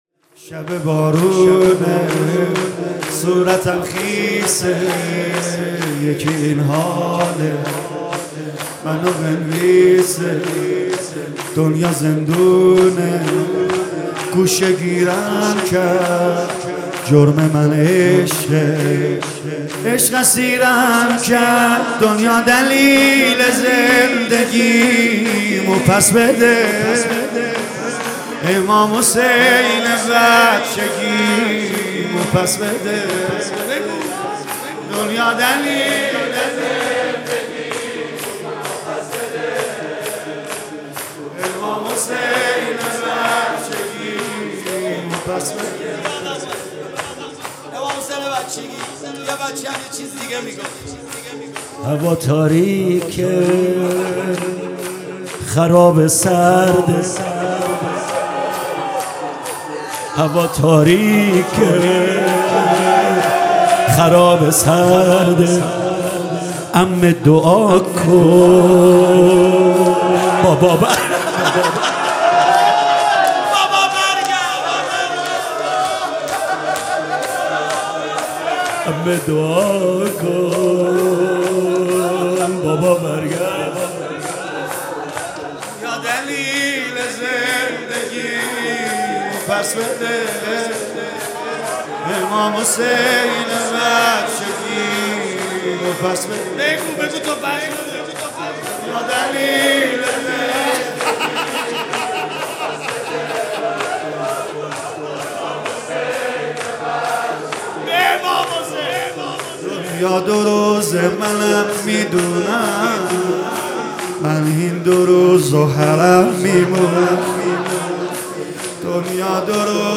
مراسم شب دوازدهم ماه مبارک رمضان